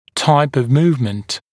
[taɪp əv ‘muːvmənt][тайп ов ‘му:вмэнт]тип движения